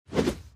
soceress_skill_shockwave_01_intro.mp3